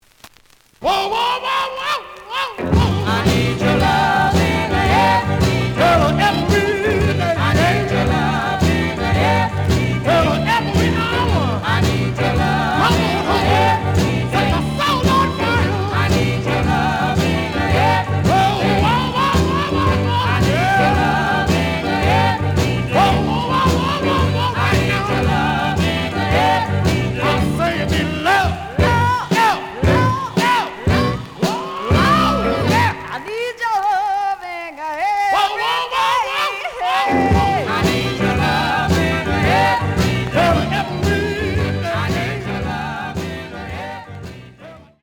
試聴は実際のレコードから録音しています。
●Format: 7 inch
●Genre: Soul, 60's Soul